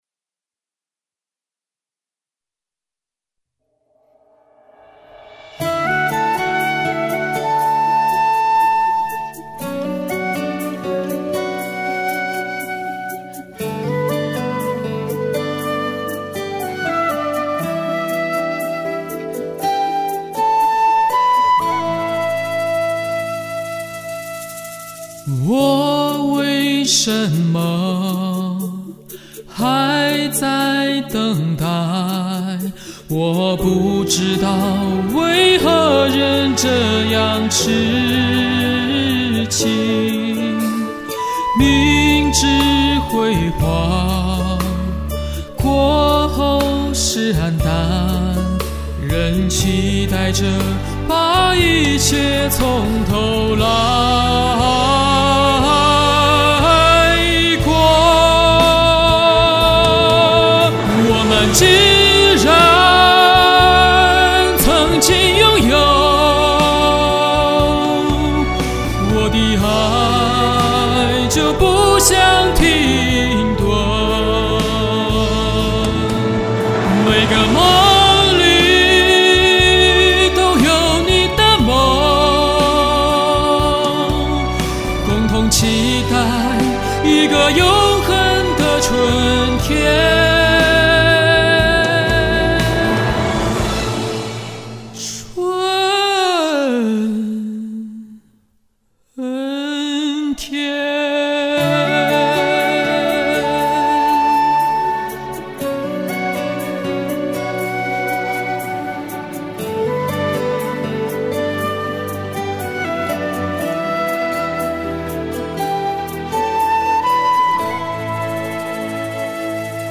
老本行啊， 就是美声。。。
这美声美得{:5_134:}